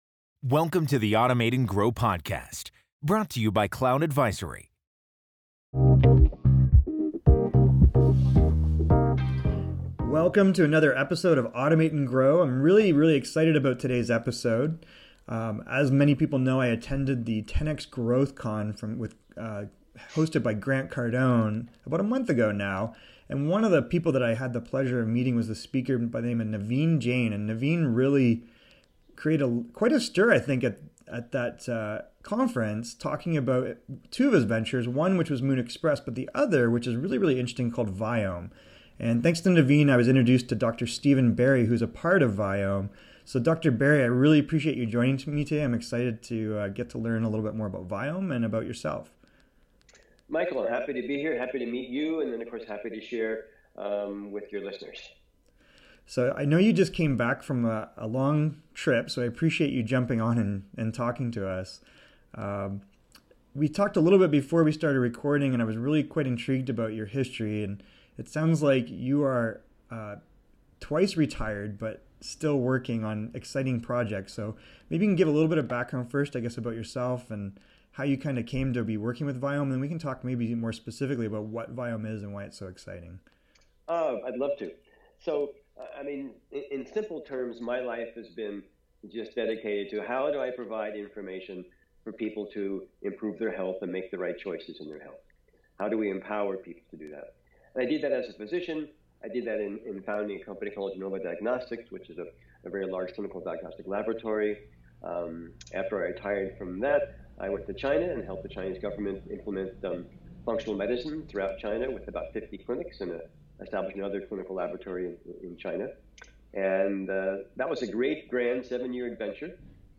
During this interview among other things that blew my mind were